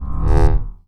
SCIFI_Energy_Pulse_08_mono.wav